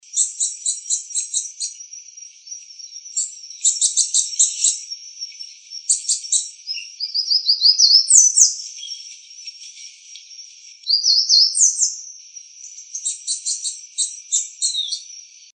Scientific name: Basileuterus culicivorus auricapilla
English Name: Golden-crowned Warbler
Life Stage: Adult
Location or protected area: Reserva Ecológica Costanera Sur (RECS)
Condition: Wild
Certainty: Photographed, Recorded vocal